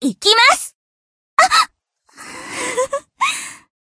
贡献 ） 分类:蔚蓝档案语音 协议:Copyright 您不可以覆盖此文件。
BA_V_Hanako_Exskill_2.ogg